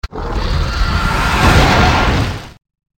b_skill_roar4.mp3